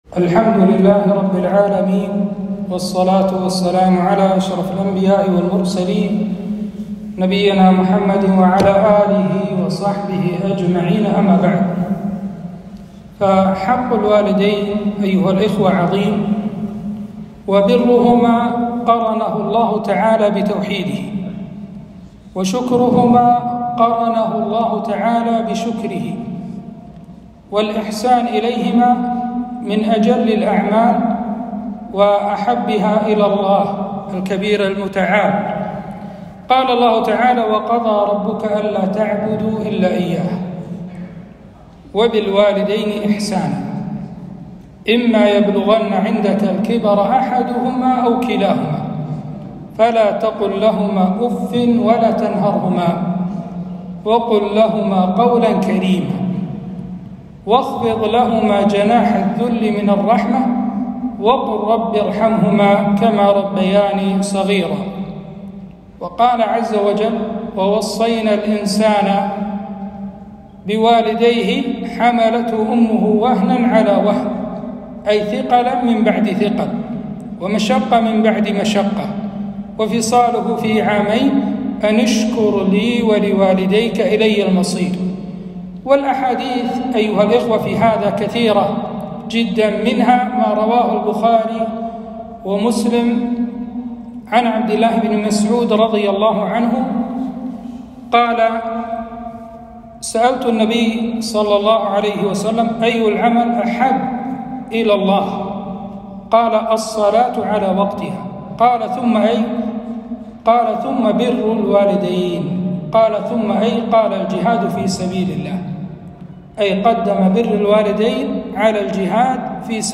كلمة - عقوق الوالدين أسبابه وأضراره